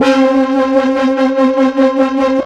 Bass x-tra 3.92.wav